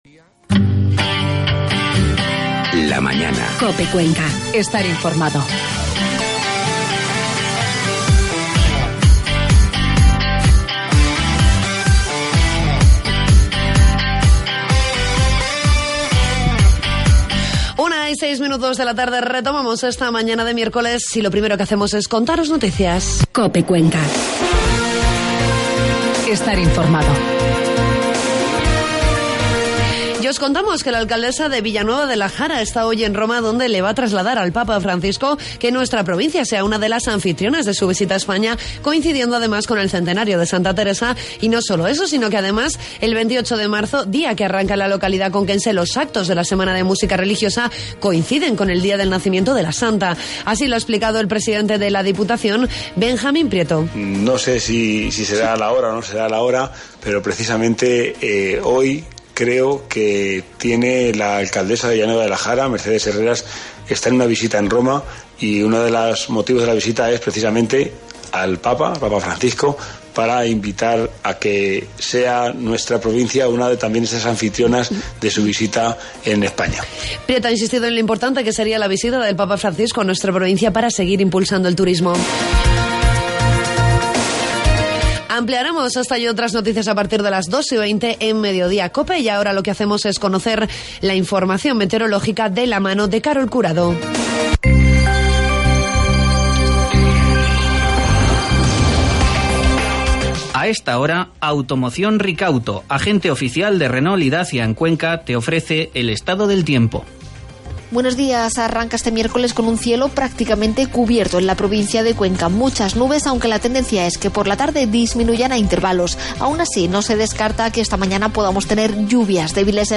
Entrevistamos a uno de los miembros de ADEVIDA Cuenca con motivo del autobús 'Cada vida importa' que ha recorrido las calles conquenses con motivo de la marcha por la vida del próximo 22 de noviembre.